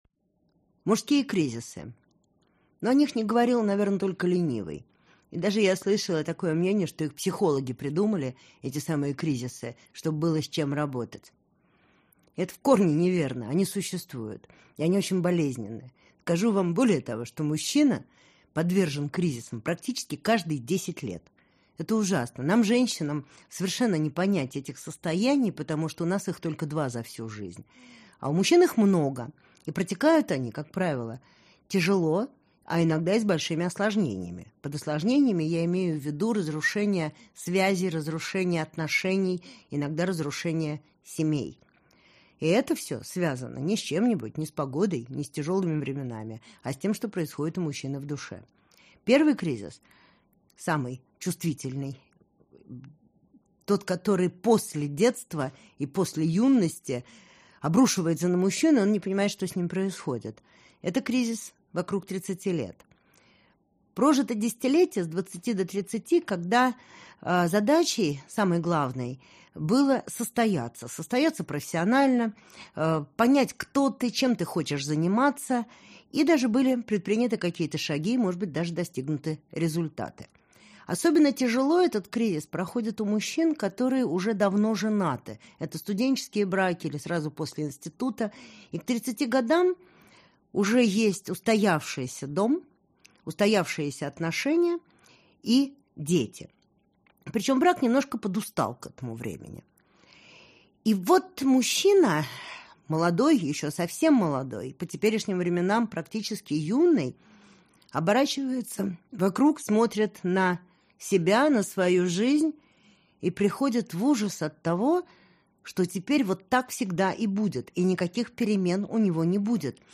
Аудиокнига Мужское. Начало. Часть 2 | Библиотека аудиокниг